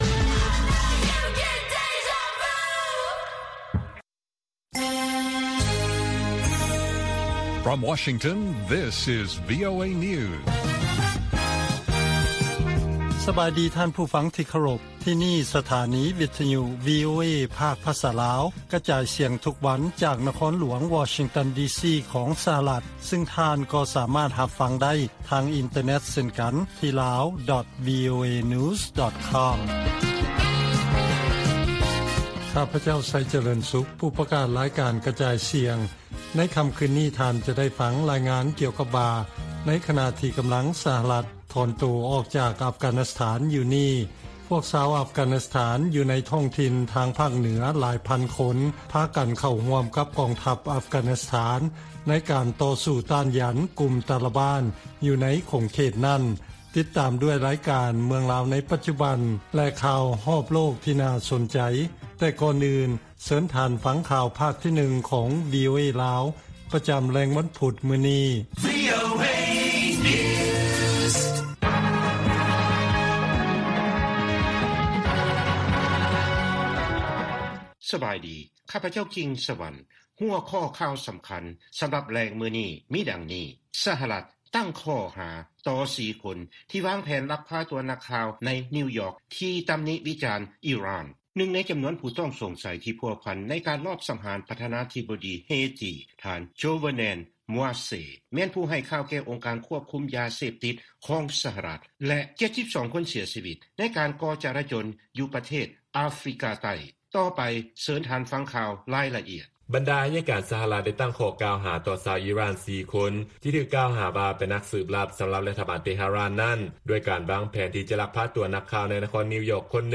ວີໂອເອພາກພາສາລາວ ກະຈາຍສຽງທຸກໆວັນ. ຫົວຂໍ້ຂ່າວສໍາຄັນໃນມື້ນີ້ມີ: 1) ສະຫະລັດຈັດສົ່ງຢາວັກຊີນ ຈອນສັນແລະຈອນສັນໃຫ້ລາວຫຼາຍກວ່າ 1 ລ້ານໂດສ. 2)ຊາວຝຣັ່ງ ເຊື້ອສາຍລາວ ສະຫຼອງວັນຊາດ 14 ກໍລະກົດ. 3) ສະຫະລັດ ຮຽກຮ້ອງໃຫ້ອາຊຽນ ດໍາເນີນການກັບມຽນມາ ແລະປະຕິເສດ ຕໍ່ການອ້າງກຳມະສິດ ທາງທະເລຂອງຈີນ ແລະຂ່າວສໍາຄັນອື່ນໆອີກ.